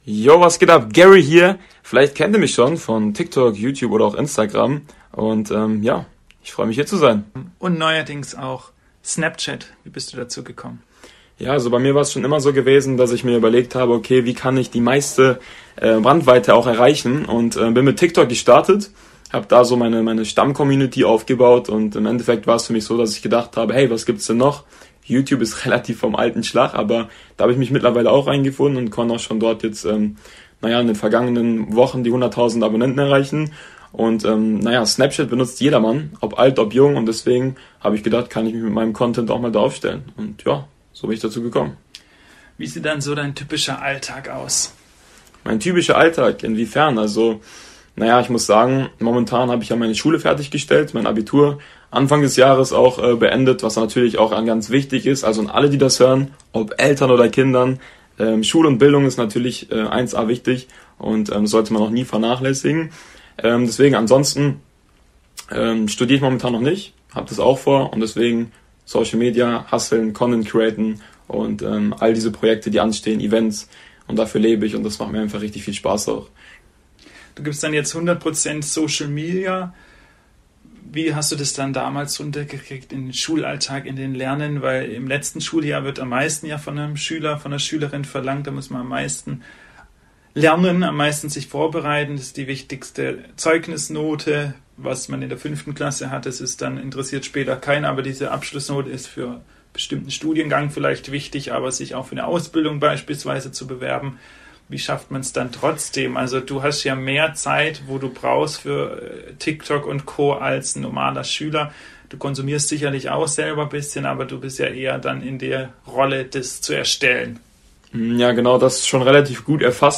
Nun spricht er als zweiter Gast der neuen Radiosendung "Gesichter & Geschichten".